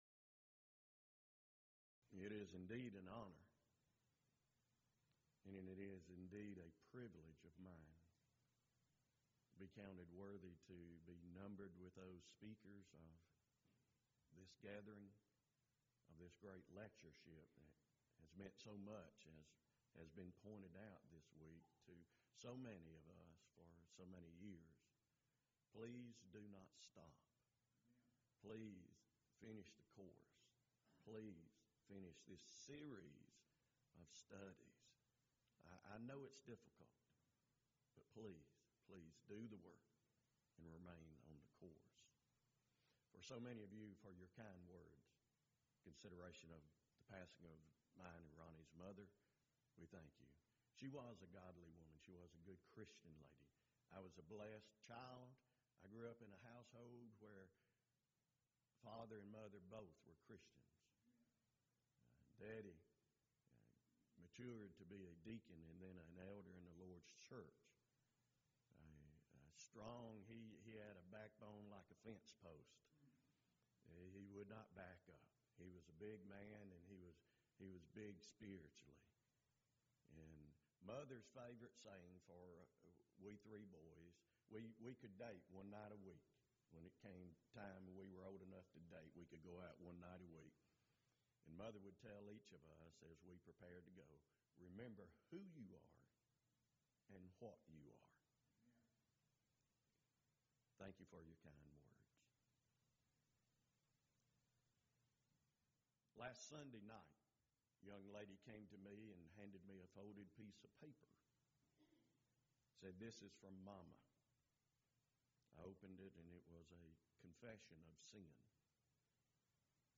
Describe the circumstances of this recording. Event: 15th Annual Schertz Lectures Theme/Title: Studies in the Psalms, Volume 2